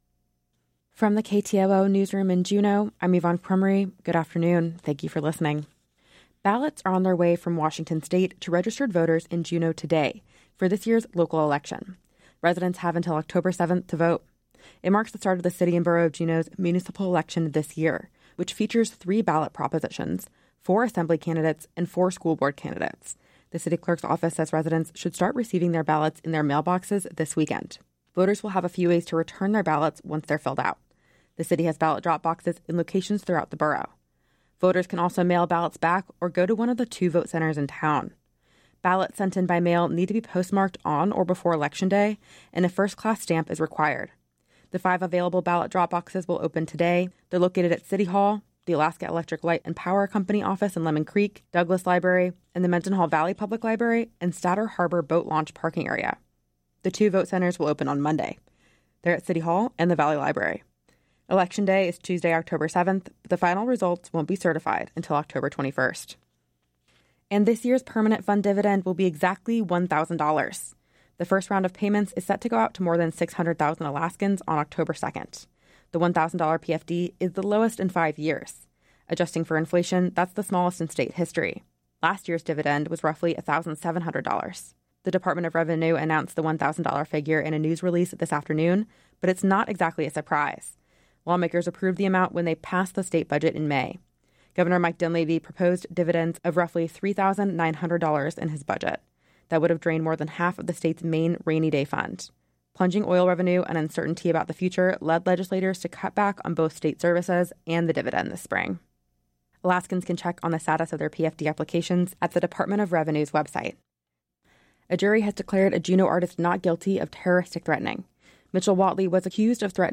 Newscast – Friday, Sept. 19, 2025 - Areyoupop